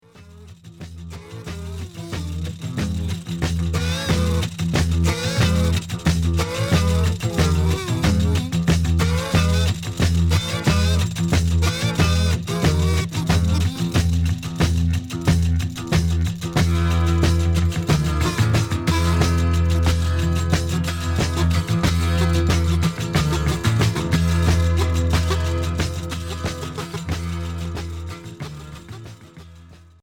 Progressif